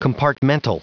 Prononciation du mot compartmental en anglais (fichier audio)
Prononciation du mot : compartmental